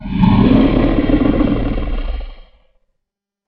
Dragon Roar
A massive dragon roaring with deep chest resonance, fire crackle, and wing leather flaps
dragon-roar.mp3